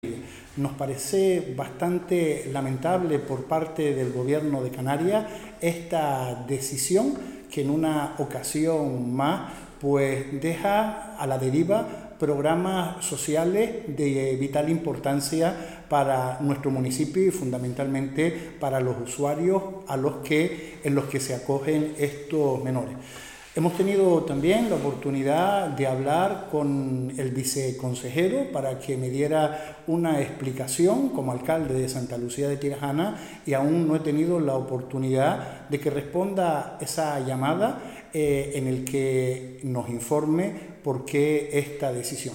AlcaldePisoTutelado16dic24.mp3